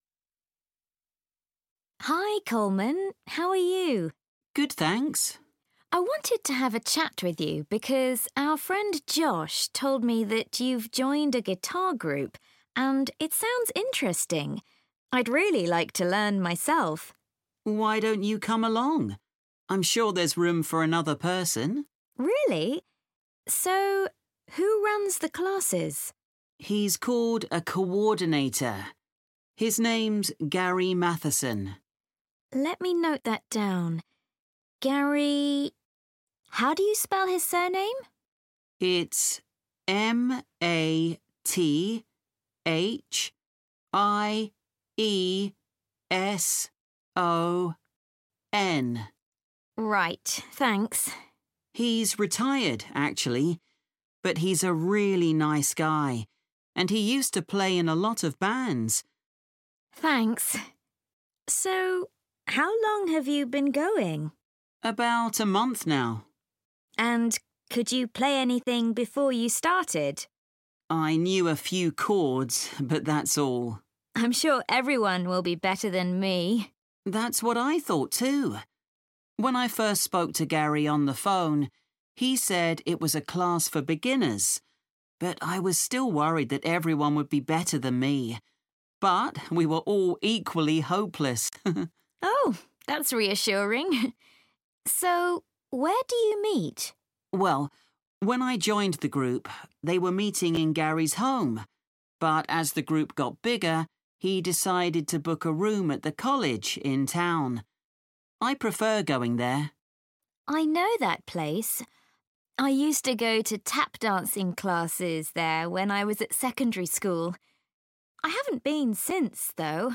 • The speaker often spells out names letter by letter, so be prepared to listen carefully.
Example: IELTS Listening Form Filling Question Type